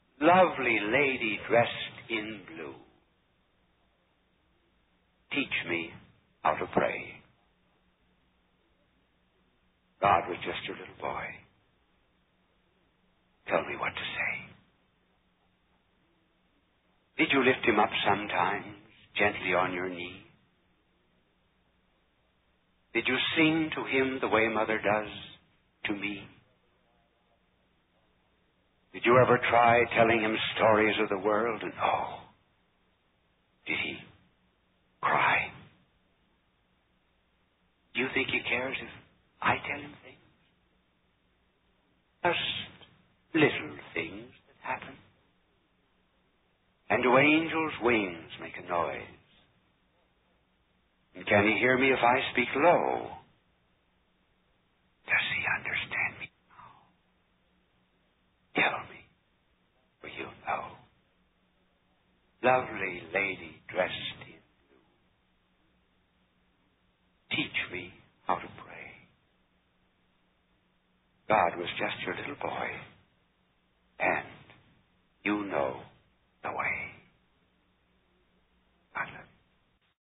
Sheen ended many of his presentations by reciting a favorite poem by Mary Dixon Thayer. Listen to Sheen reading the poem: